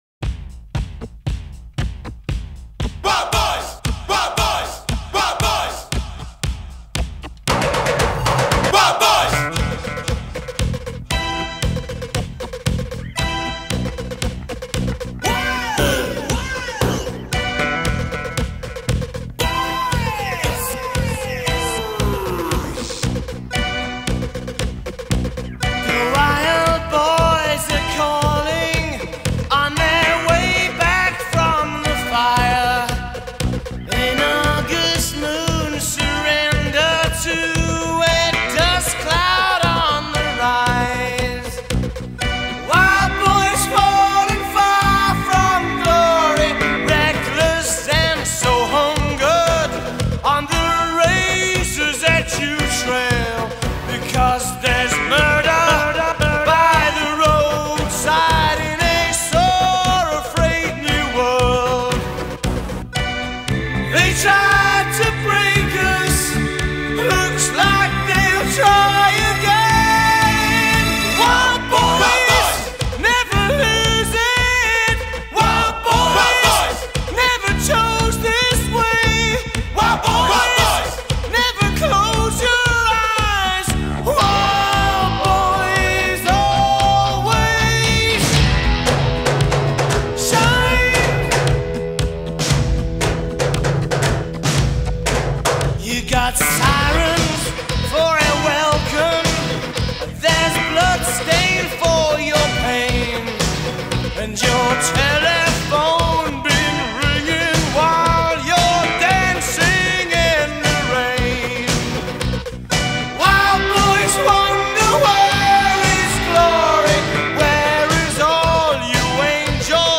мощную смесь рок-звучания и синтезаторной музыки